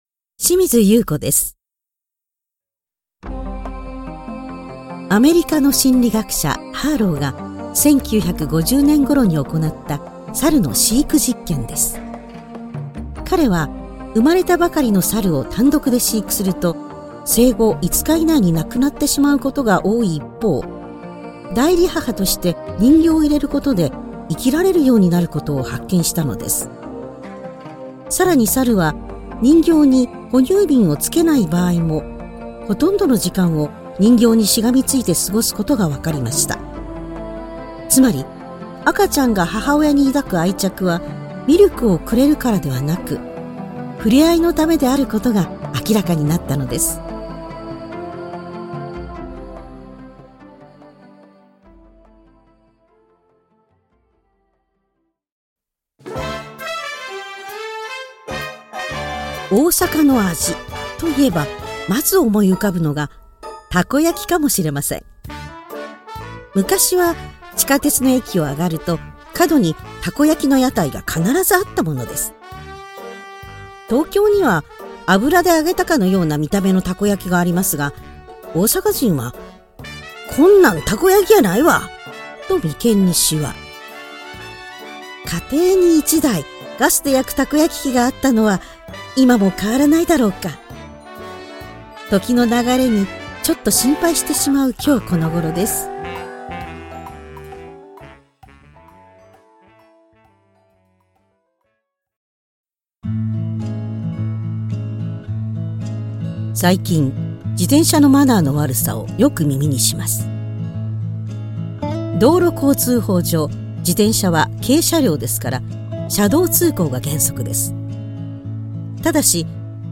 表現力のある落ち着いた声